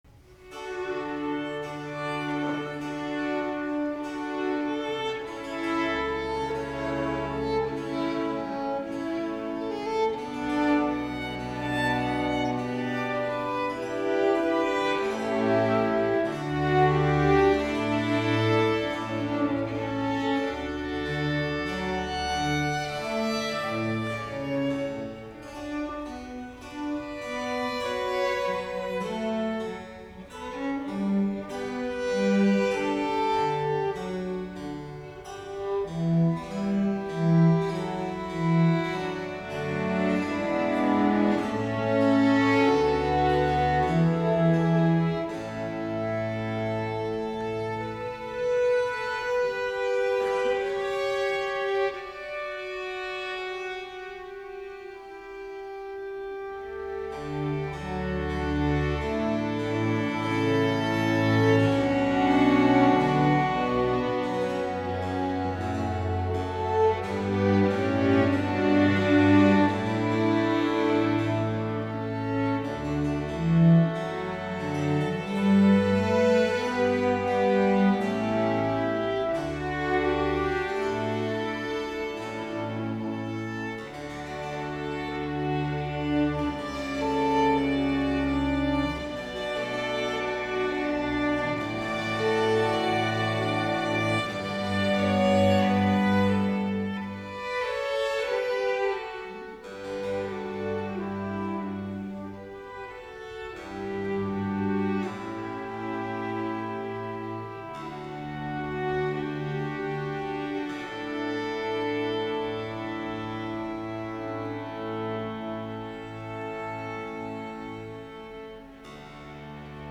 Concerto Italiano
Registrazioni dal vivo di alcuni concerti del Festival di Musica Antica di Salerno